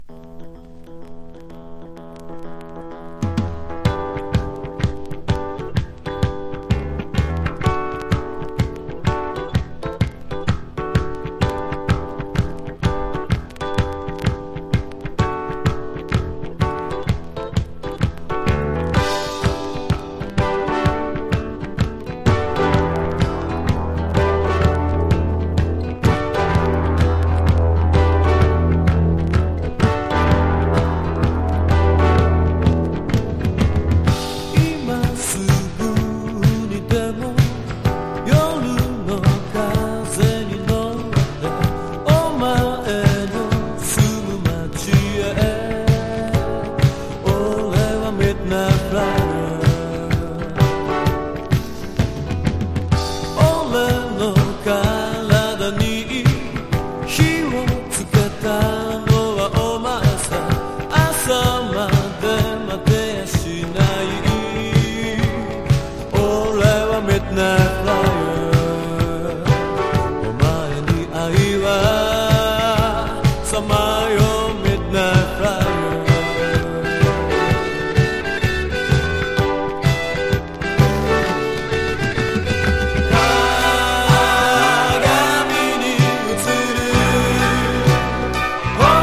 60-80’S ROCK# CITY POP / AOR